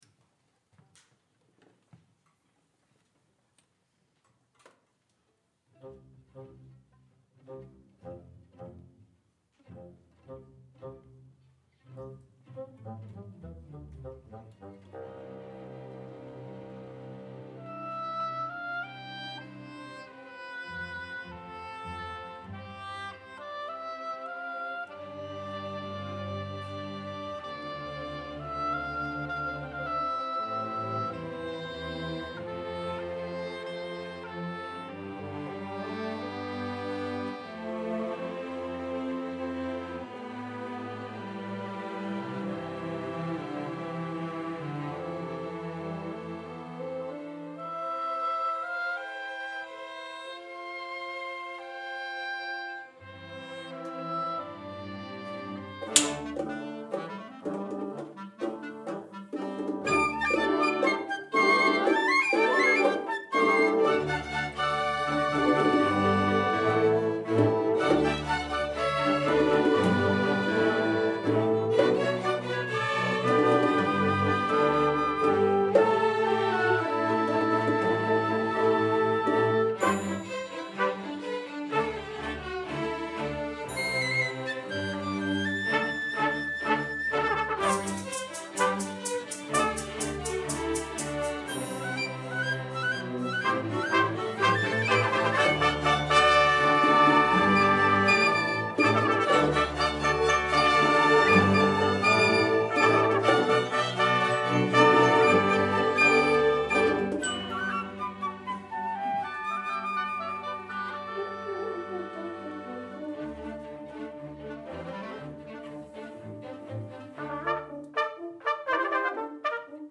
for Orchestra (2016)